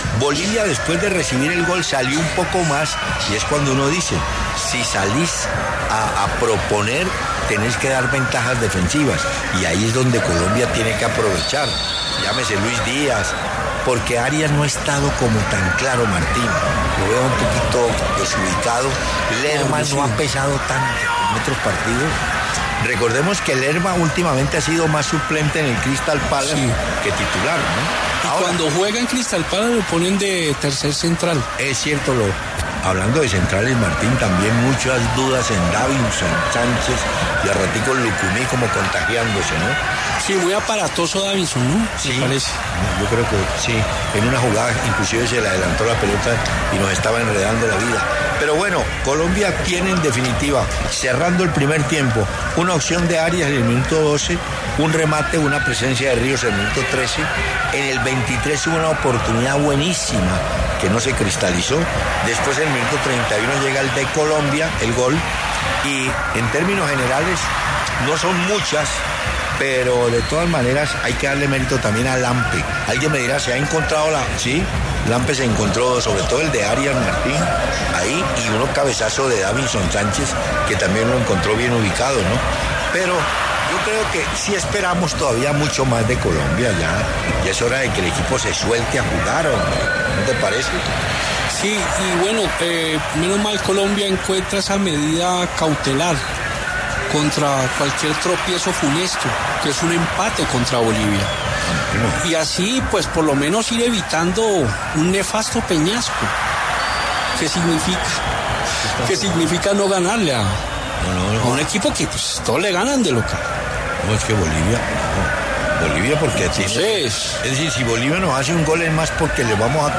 El periodista deportivo Hernán Peláez analizó el primer tiempo de la Selección Colombia ante Bolivia, partido que se juega en el estadio Metropolitano de Barranquilla.